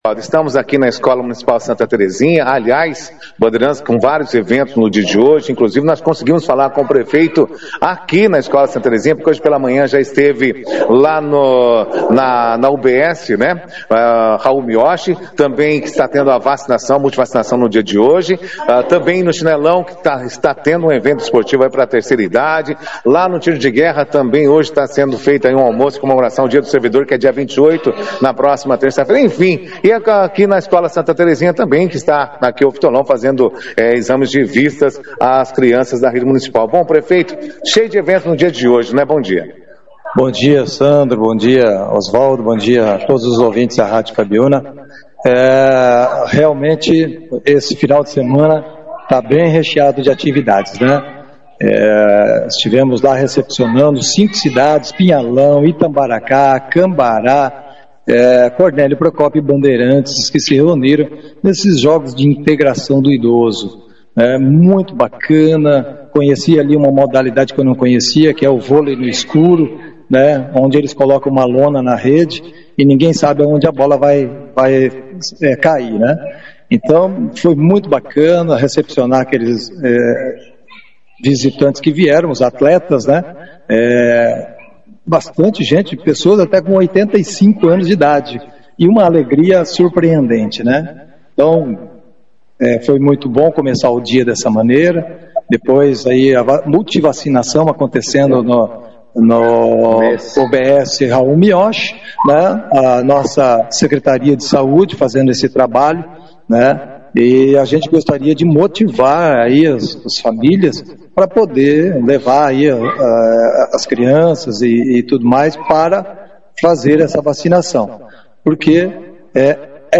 O prefeito de Bandeirantes, Jaelson Matta, o vice-prefeito Mano Vieira e a secretária de Educação, Aline Neves, participaram neste sábado (25) da edição do jornal Operação Cidade, destacando importantes ações que estão acontecendo no município.